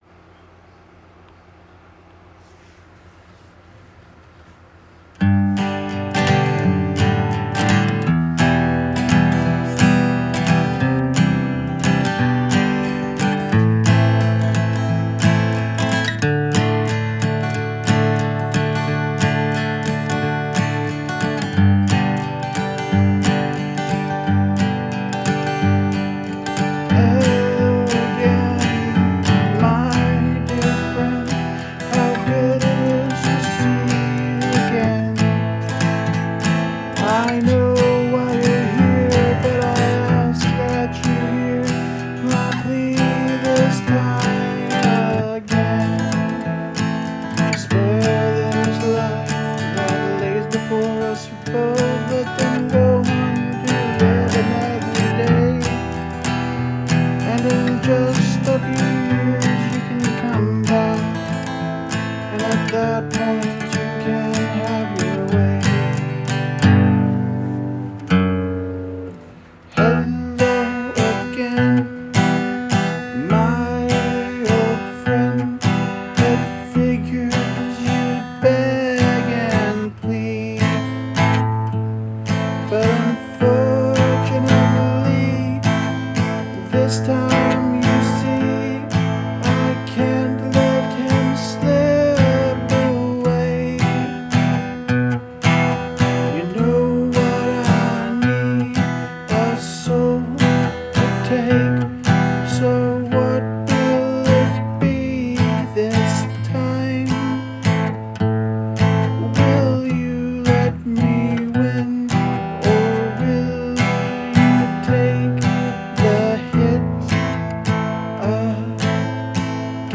It also switches time signatures: something I have never done in a song before.
folk rock country